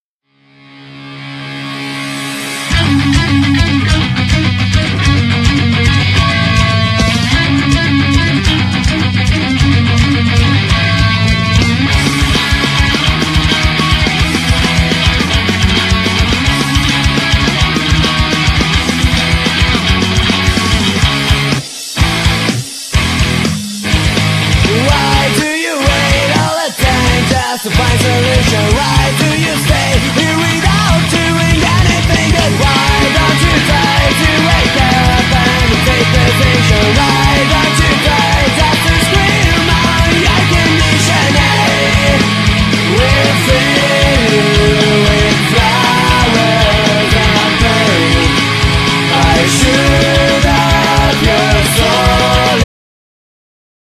Genere : Punk HC